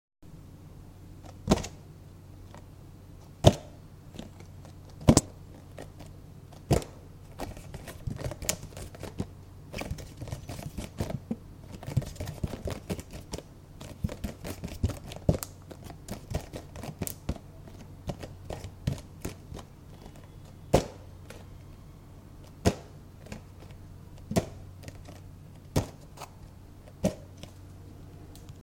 Download Box sound effect for free.